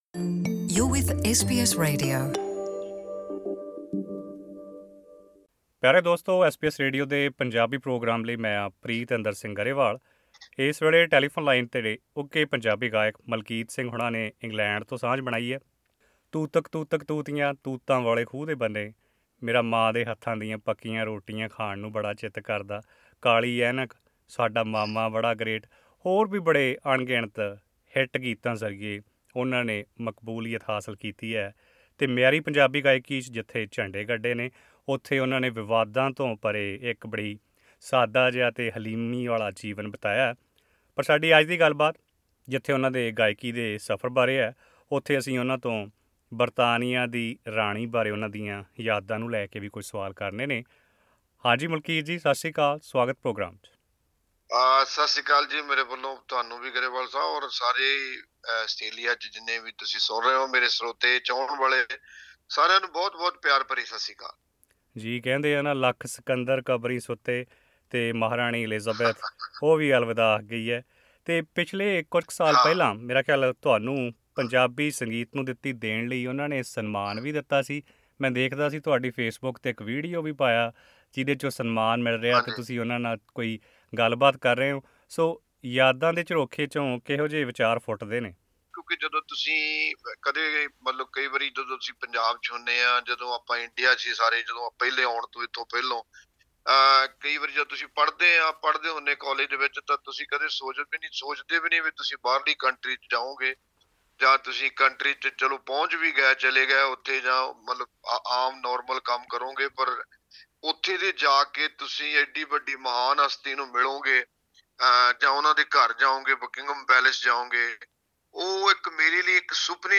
Malkit Singh, the first Punjabi singer to be honoured with Member of Most Excellent Order of the British Empire (MBE) in 2008 recalls his meeting with Queen Elizabeth II in an exclusive interview with SBS Punjabi.